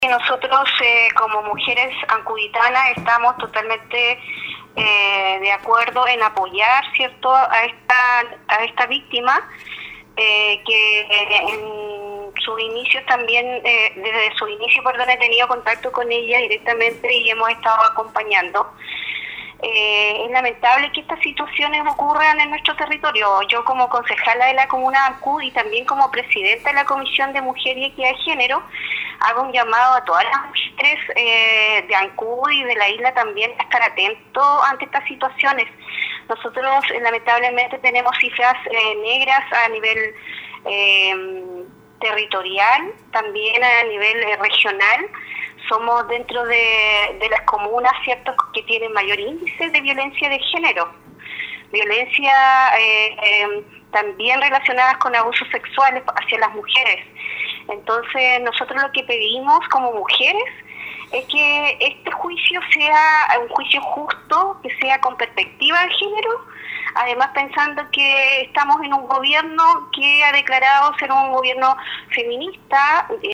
Del mismo modo, la concejala de la comuna de Ancud, Ruth Caicheo, a su vez, quien preside la comisión de mujer y equidad de género, subrayó en la importancia de esta etapa procesal donde se espera por una sentencia justa.